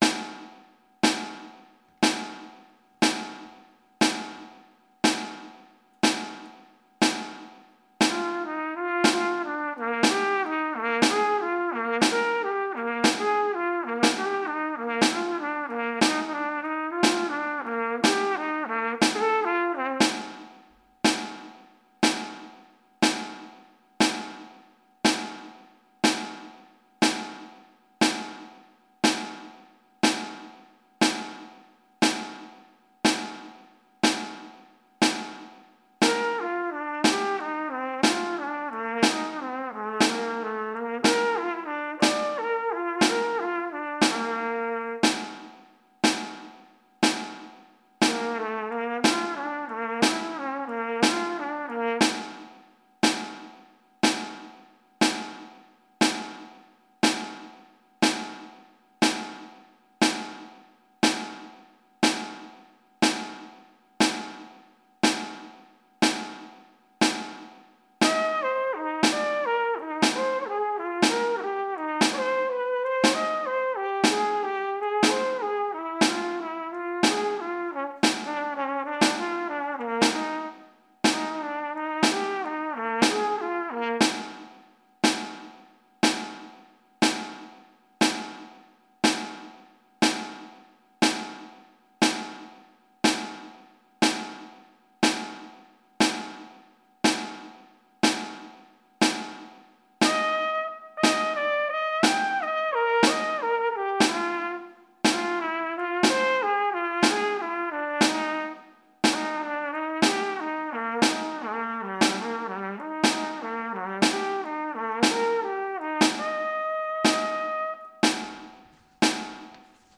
Repeat the “20 Minutes Warmup” but this time continue up to a high “E”. Then continue back down when the recording descends.